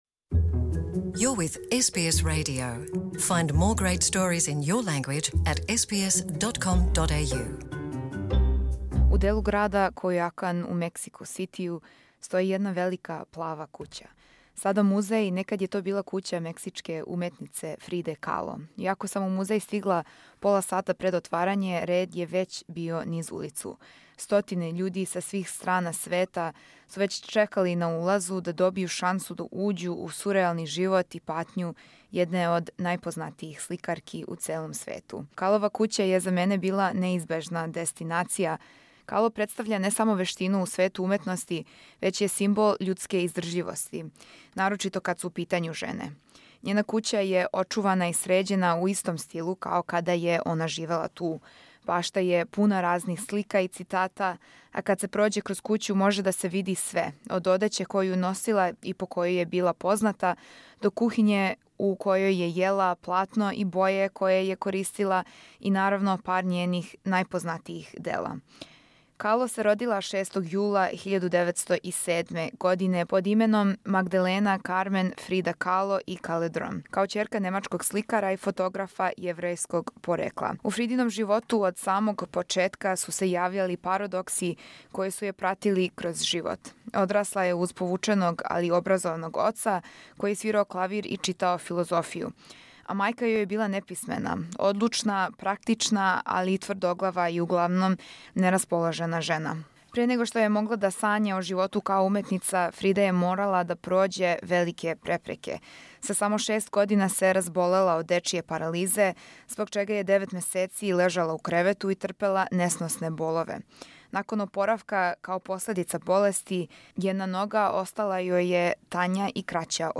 Данас ћемо чути путописну репортажу из Мексика током посете музеју посвећеном једној од најпознатијих светских сликарки.